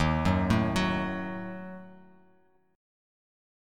D#dim chord